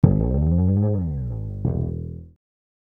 E SLIDE UP.wav